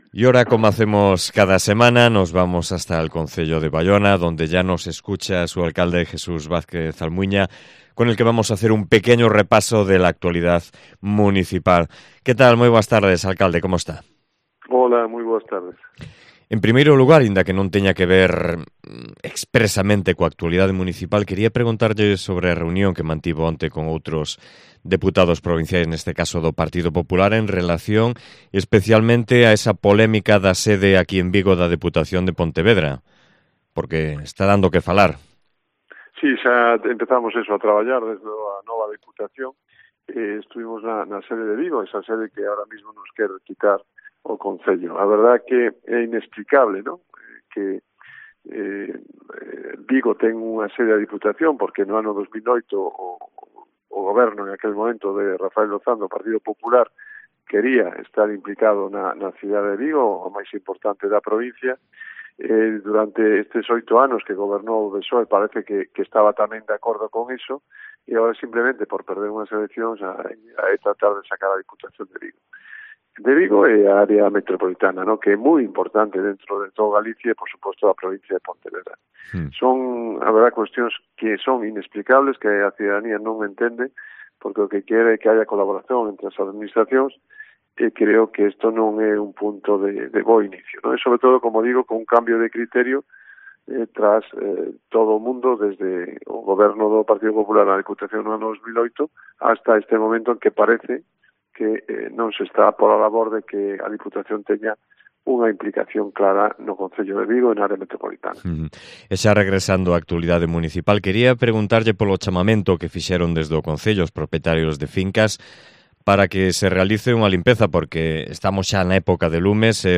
Entrevista con el alcalde de Baiona, Jesús Vázquez Almuiña